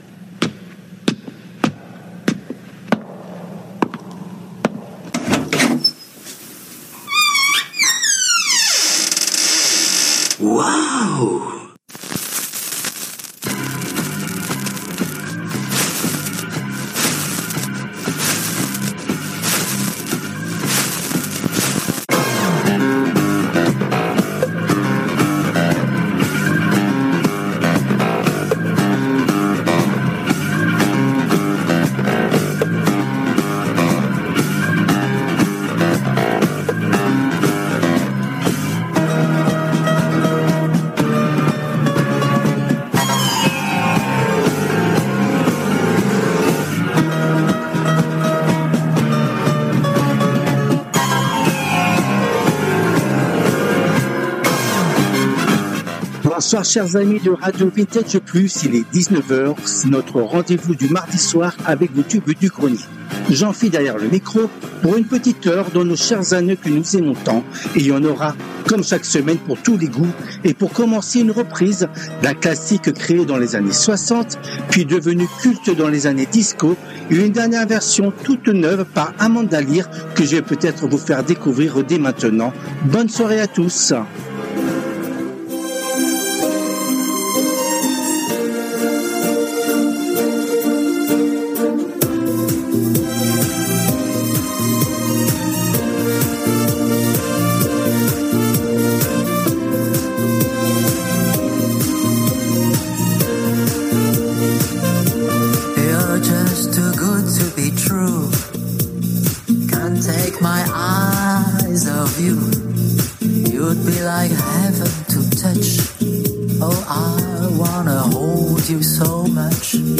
Les TUBES DU GRENIER constituent la première émission hebdomadaire phare de la semaine sur RADIO VINTAGE PLUS. Cette émission a été diffusée en direct le mardi 04 mars 2025 à 19h depuis les studios de RADIO RV+ à PARIS .